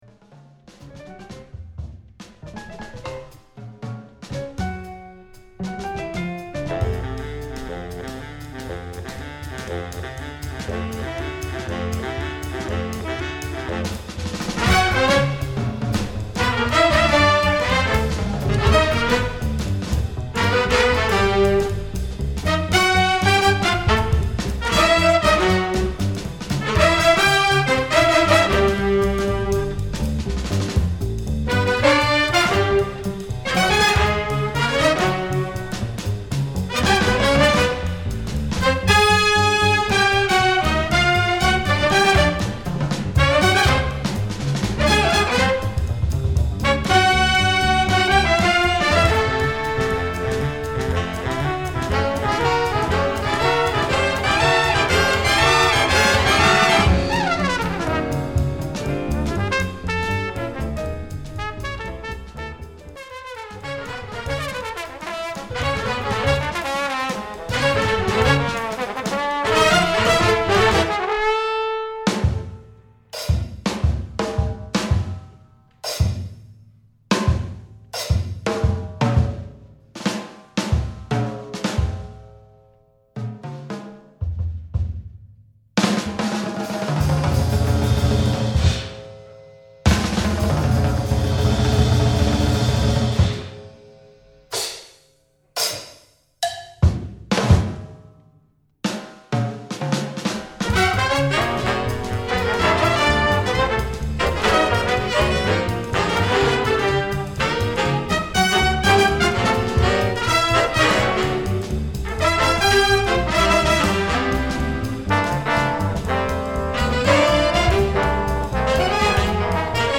Big Band Charts
Lead Trumpet Range: high G
Solos: open, drums